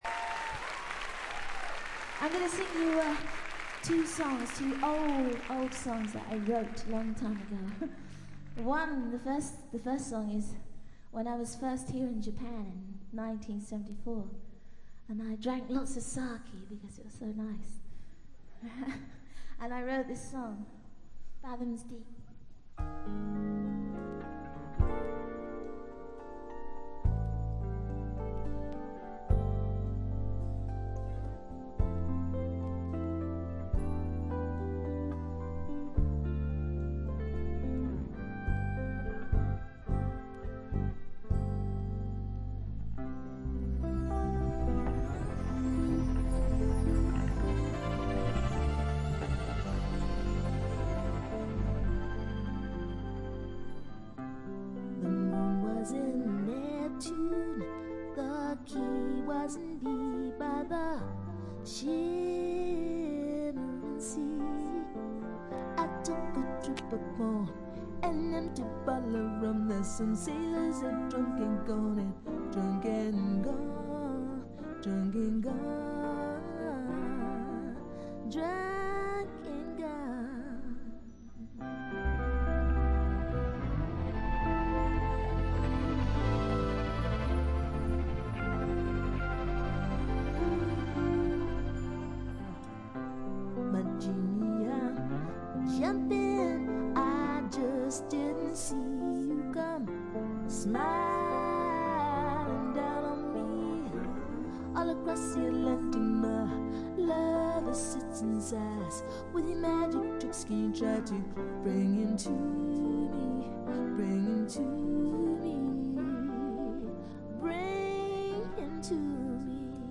95年10月20、21日に渋谷On Air Eastで行われたライブをほぼステージに忠実に再現した2枚組です。
試聴曲は現品からの取り込み音源です。
Vocals, Acoustic Guitar, Percussion
Bass
Drums